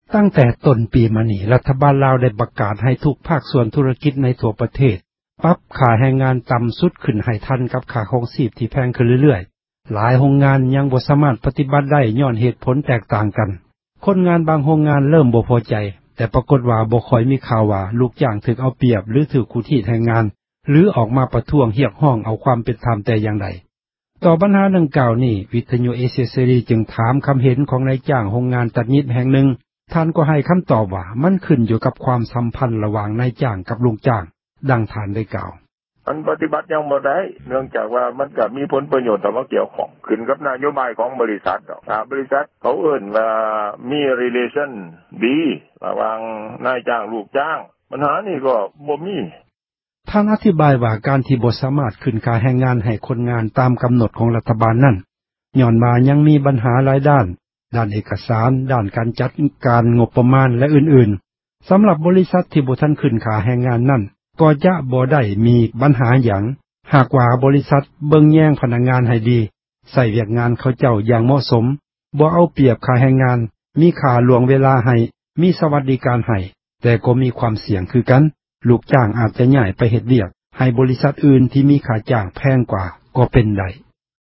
ຕໍ່ບັນຫາ ດັ່ງກ່າວນີ້ ວິທຍຸ ເອເຊັຽເສຣີ ຈື່ງຖາມຄໍາເຫັນ ຂອງນາຍຈ້າງ ໂຮງງານ ຕັດຫຍິບ ແຫ່ງນື່ງ ທ່ານກໍໃຫ້ ຄໍາຕອບວ່າ ມັນຂື້ນຢູ່ກັບ ຄວາມສັມພັນ ລະຫ່ວາງ ນາຍຈ້າງ ກັບລູກຈ້າງ.